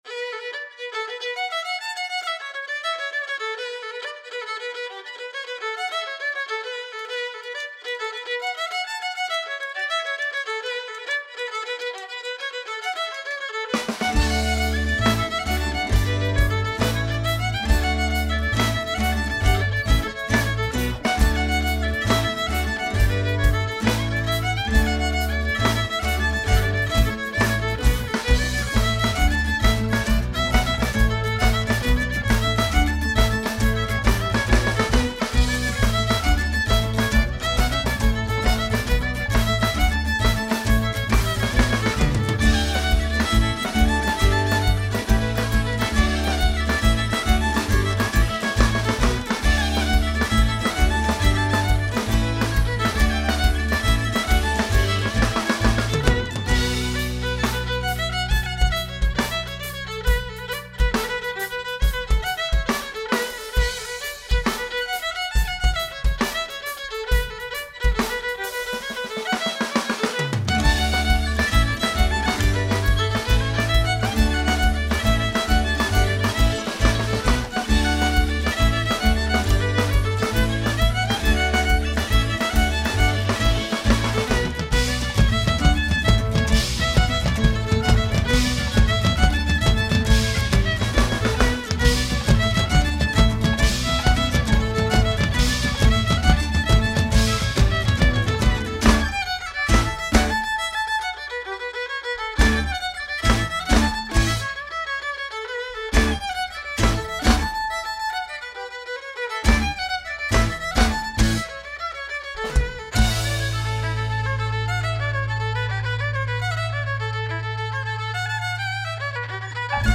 four-part vocal harmonies